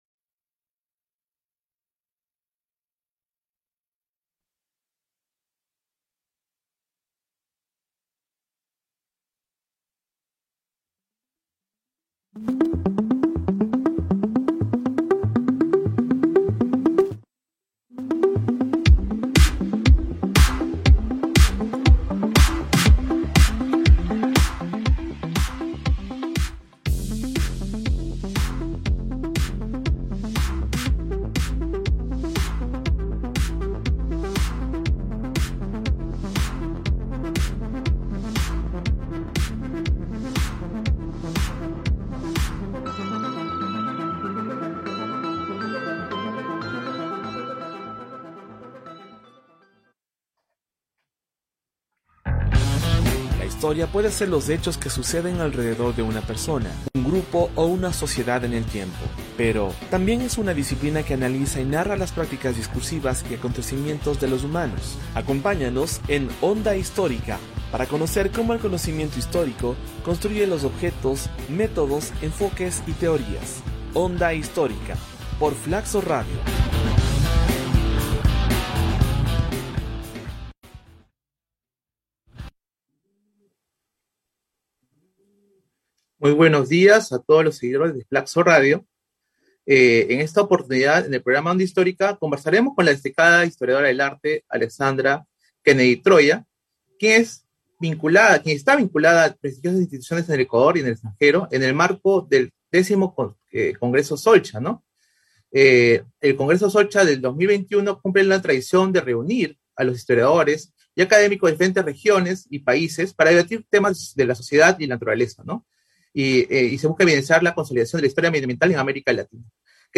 ¡Escucha la entrevista completa sobre este apasionante tema!